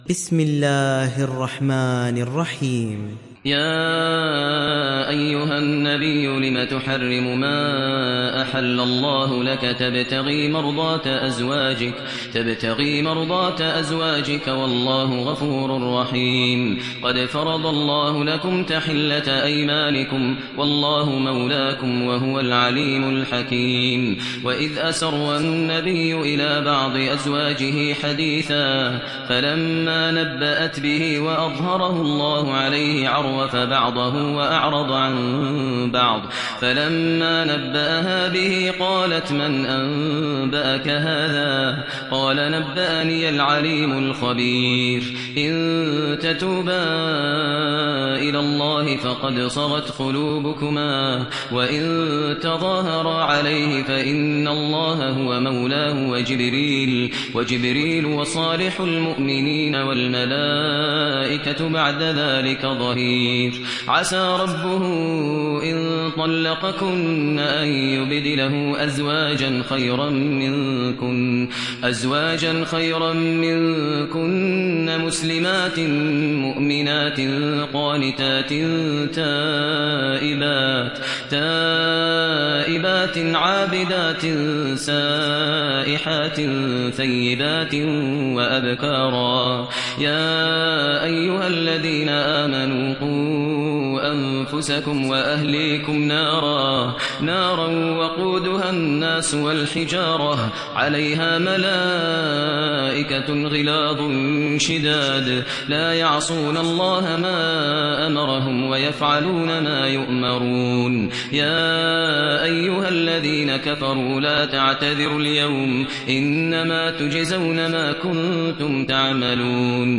تحميل سورة التحريم mp3 بصوت ماهر المعيقلي برواية حفص عن عاصم, تحميل استماع القرآن الكريم على الجوال mp3 كاملا بروابط مباشرة وسريعة